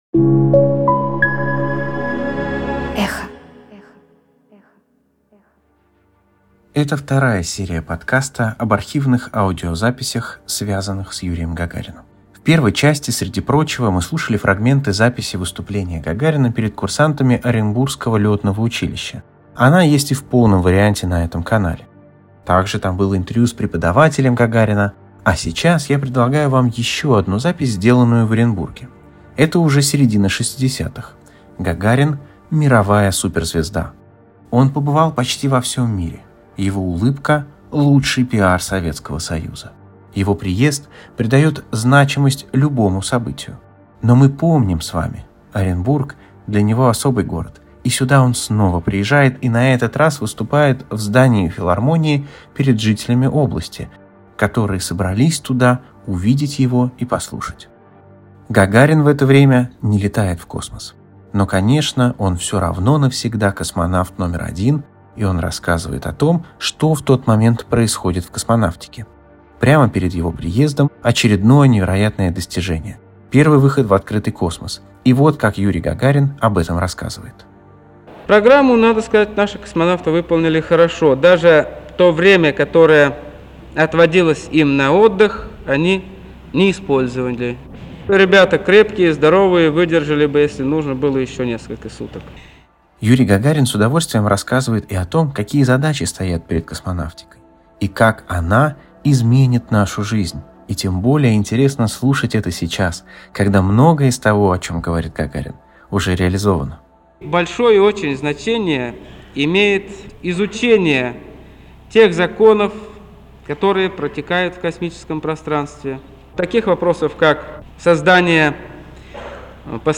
Голос Юрия Гагарина — на тот момент уже мировой суперзвезды — рассказывает о том, как советского космонавта встречали во Франции, о светлом будущем и полетам к далеким планетам….